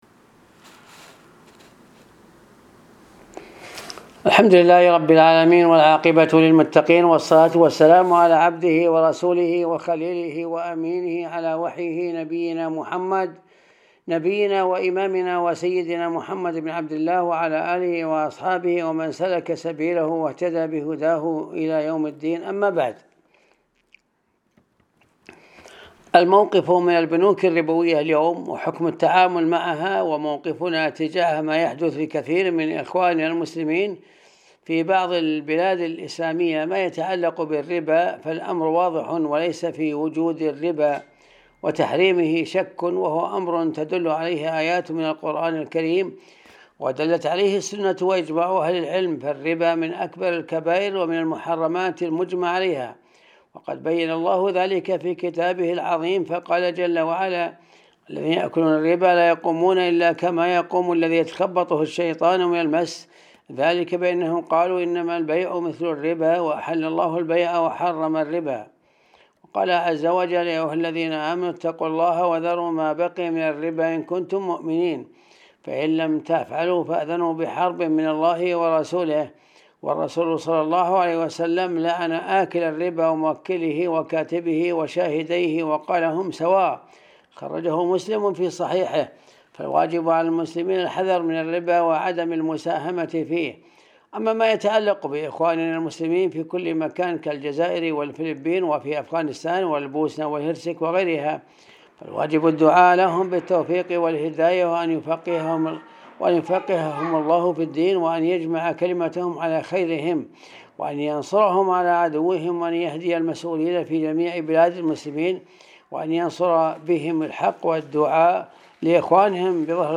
الدرس 280 ج 5 الموقف من البنوك الربوية